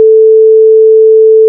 y = numpy.sin(2*numpy.pi*440*t)                     # pure sine wave at 440 Hz
这是 440Hz 的纯音。